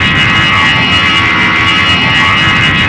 cfm-buzz.wav